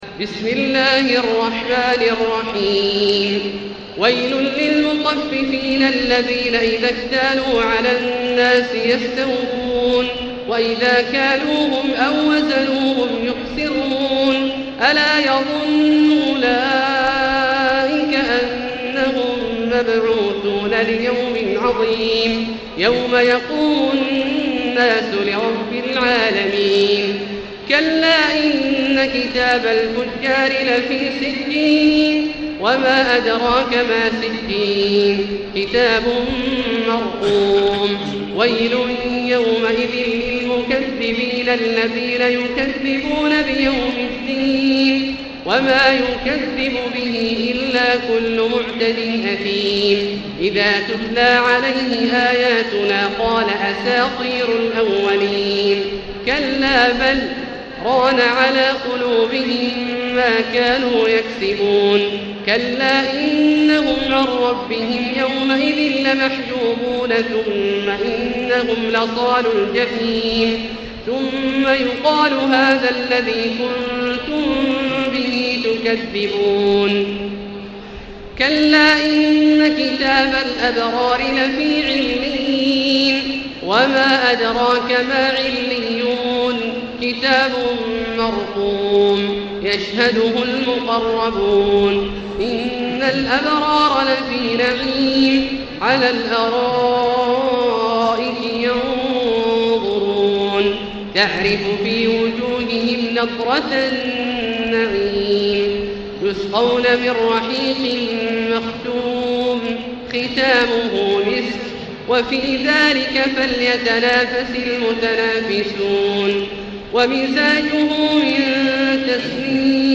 المكان: المسجد الحرام الشيخ: فضيلة الشيخ عبدالله الجهني فضيلة الشيخ عبدالله الجهني المطففين